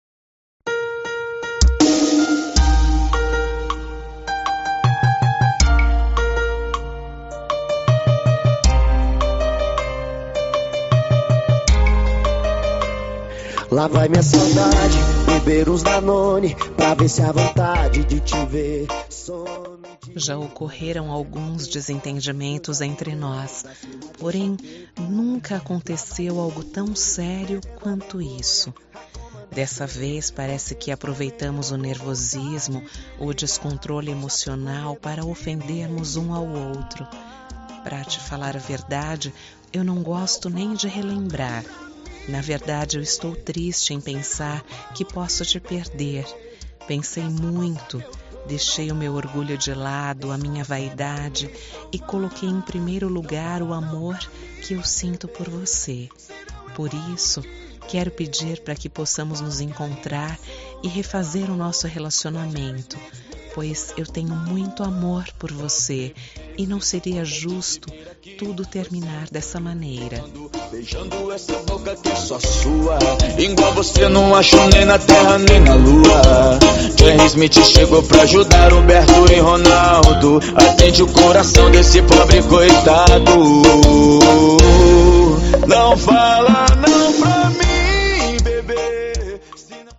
Telemenssagem de Reconciliação – Voz Feminina – Cód: 7547